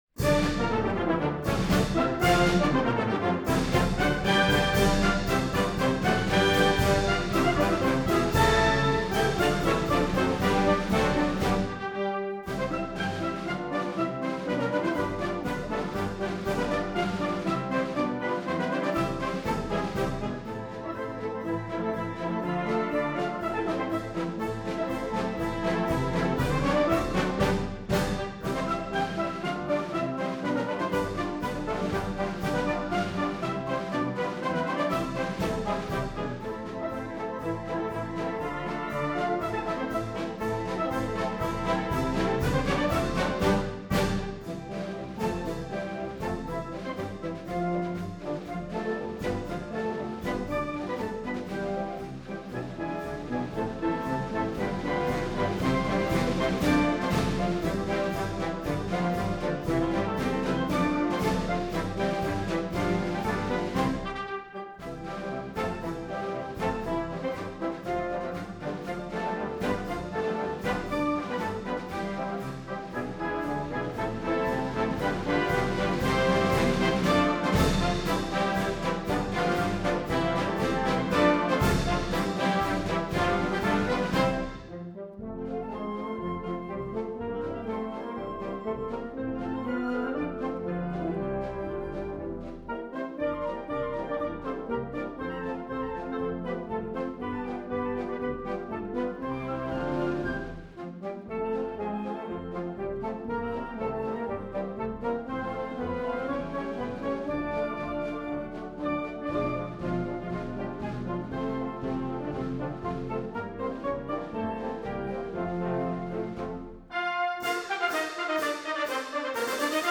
University of Illinois March from The Complete Marches of John Philip Sousa: Vol. 7